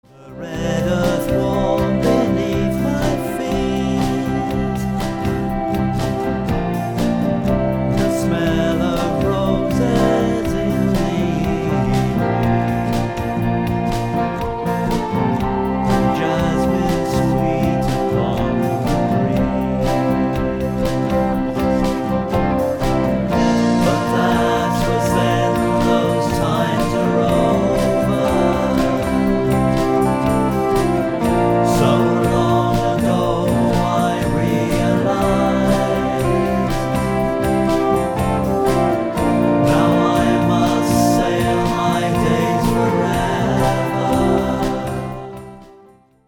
demo